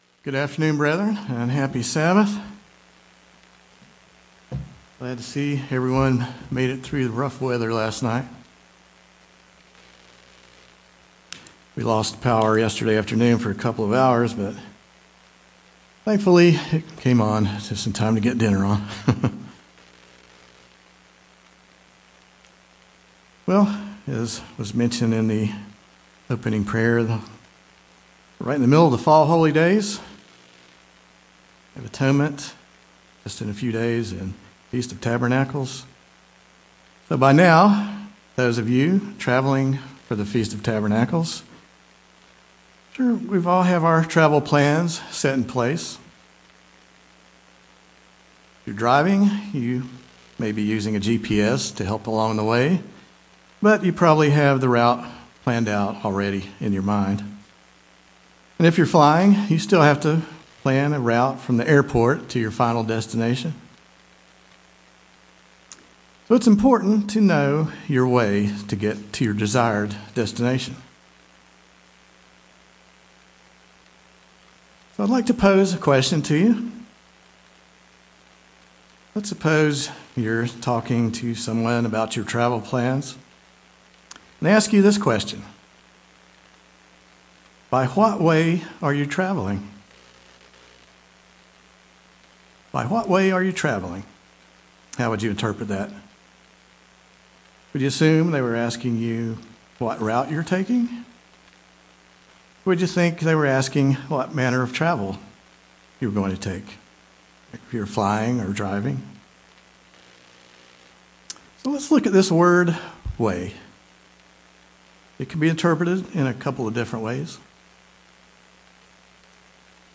Given in Greensboro, NC